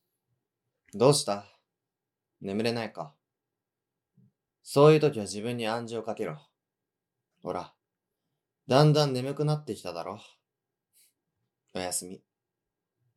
12,250投稿を記念して、メンバーのおやすみボイスを2025年12月31日までお届けします。
アロハ おやすみボイス
アロハおやすみボイス.wav